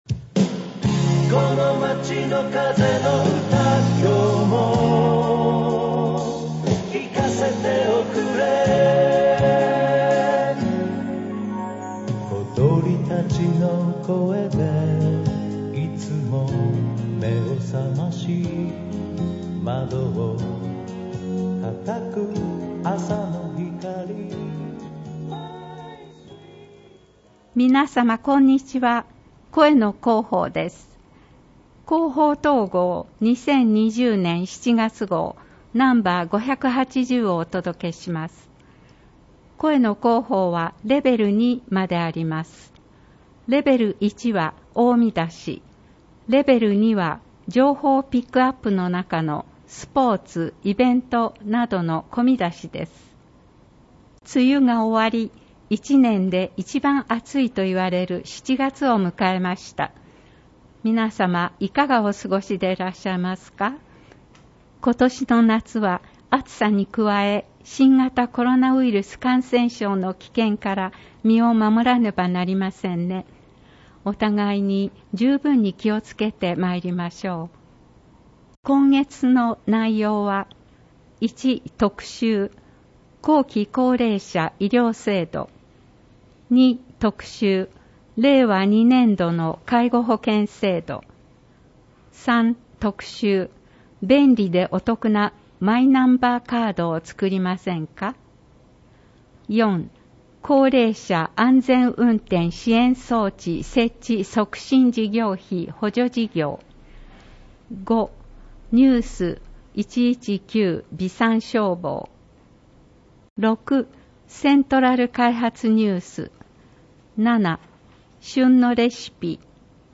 広報とうごう音訳版（2020年7月号）